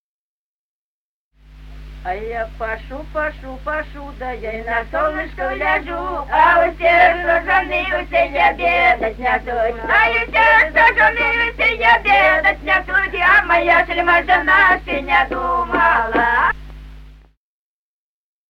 Песни села Остроглядово. А я пашу, пашу, пашу (плясовая).